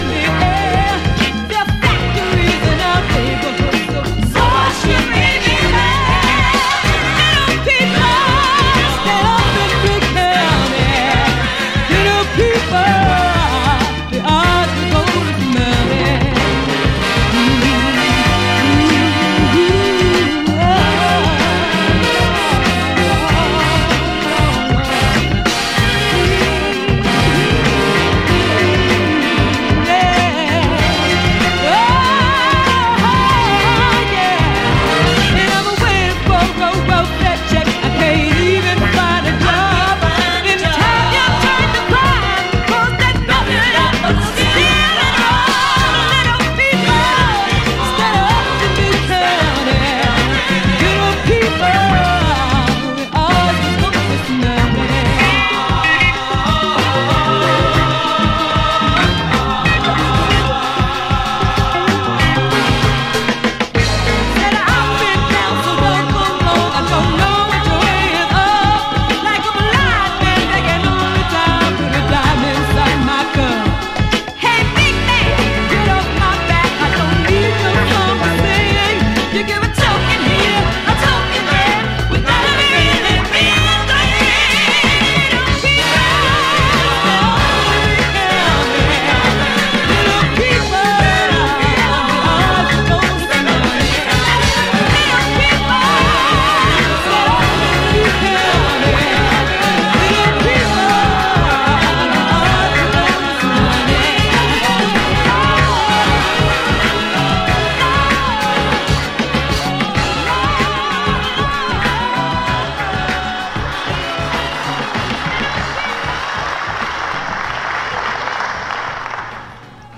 ソウル、ファンク、ディスコのゴスペルとの繋がりを提示するミックスCDという形をとった超大作！